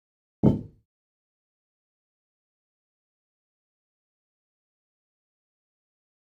Звуки гантелей
Звук гантели на полу